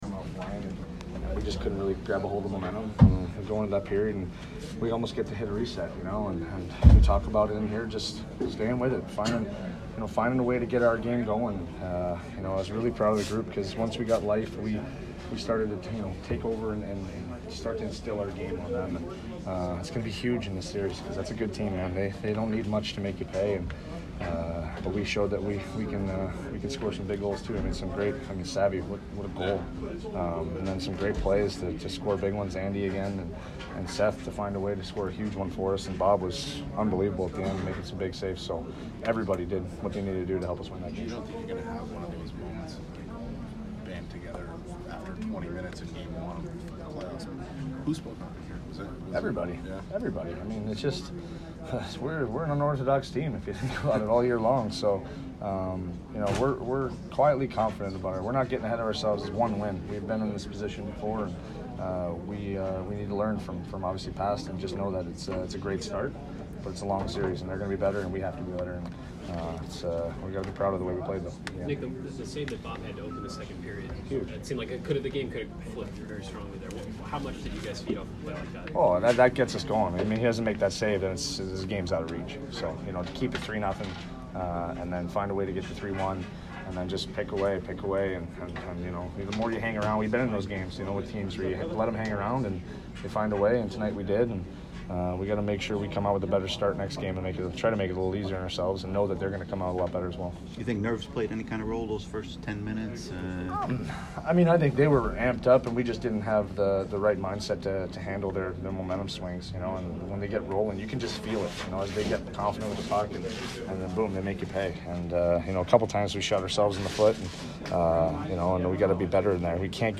Nick Foligno post-game 4/10